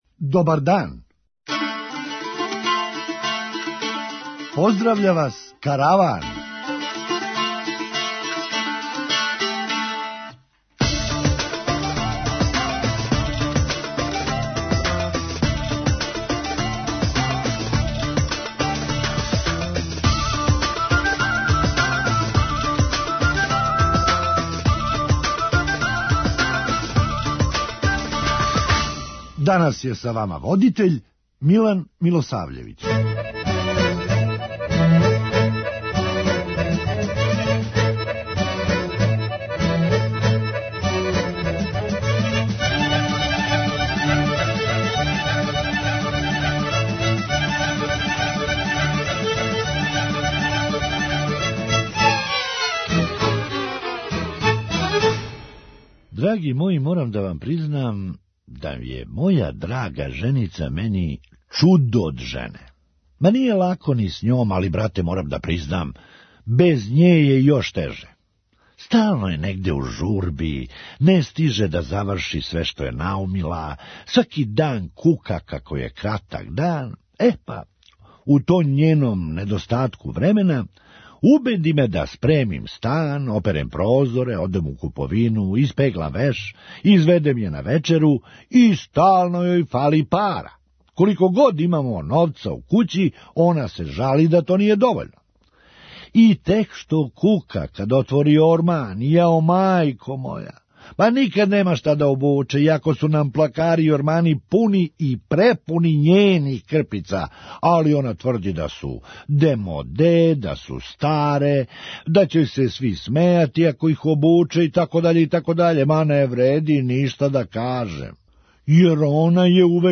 Хумористичка емисија
Ово је неким крупним рибама утерало страх у кости. преузми : 9.49 MB Караван Autor: Забавна редакција Радио Бeограда 1 Караван се креће ка својој дестинацији већ више од 50 година, увек добро натоварен актуелним хумором и изворним народним песмама.